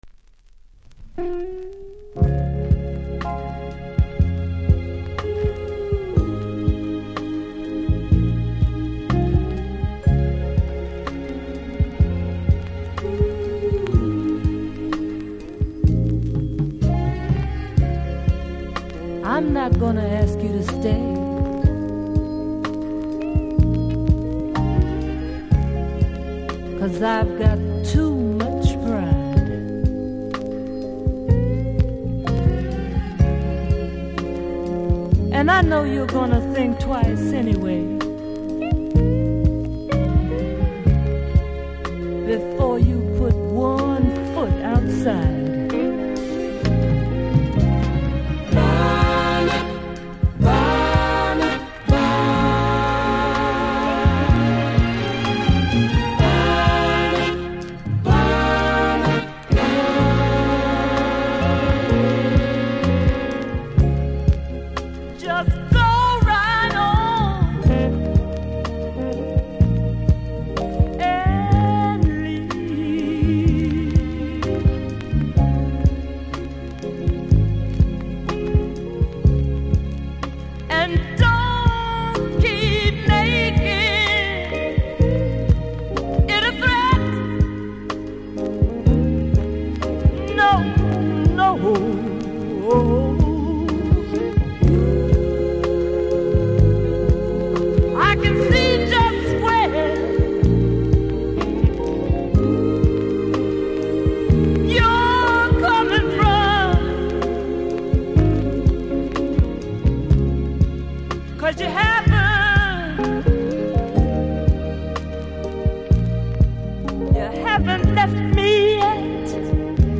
1975, Jamaica Blank, Vinyl
キズは多めですが音はそこまでノイズ感じないので試聴で確認下さい。